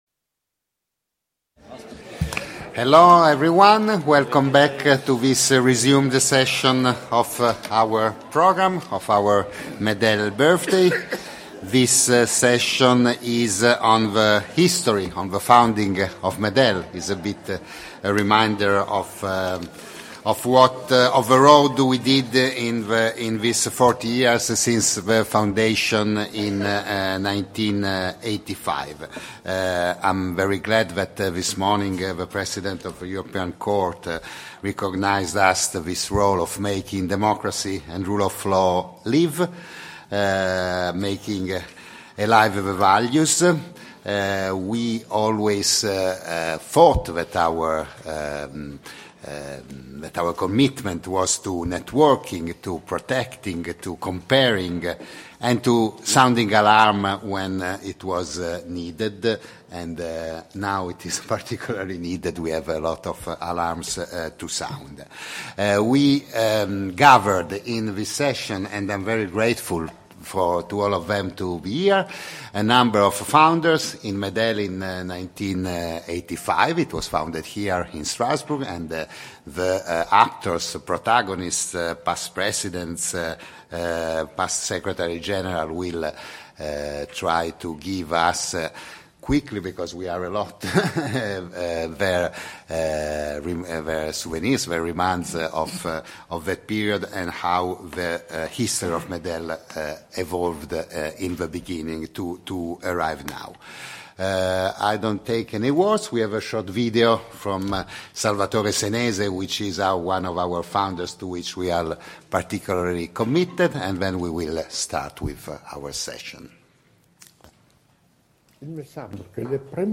Sessions & Key Speeches from MEDEL’s 40th Anniversary Conference – 3 June 2025, Strasbourg
ROUND_TABLE-MEDEL_S-VISIONARY-PROJECT.mp3